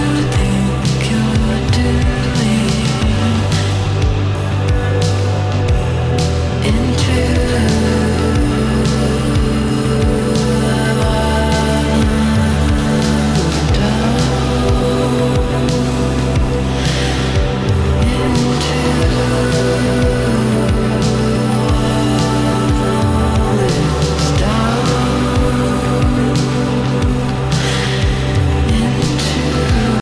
e talvez marcante na pop/rock de 2025